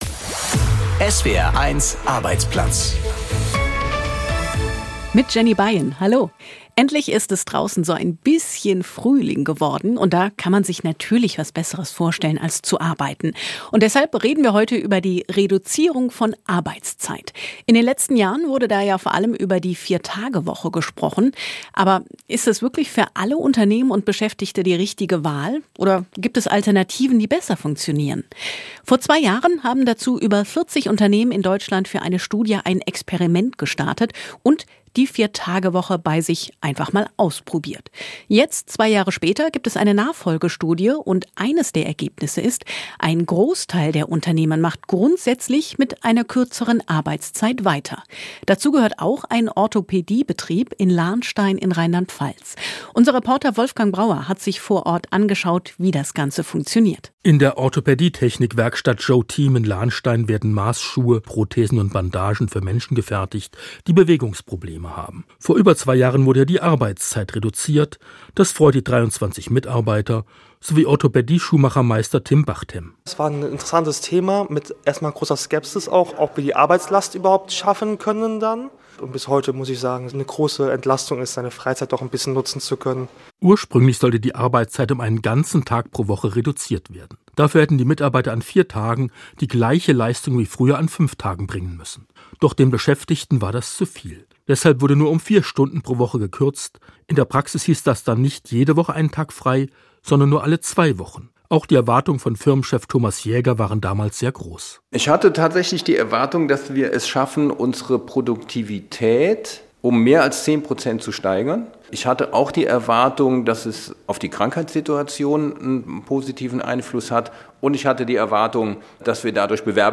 Eine Studie zur 4-Tage-Woche zeigt: viele Unternehmen wollen dabei bleiben, aber nicht alle. Wir besuchen einen Betrieb in Lahnstein, der dauerhaft umgestellt hat ++ Wieso ist die 4-Tage-Woche nicht für alle Unternehmen das richtige Modell?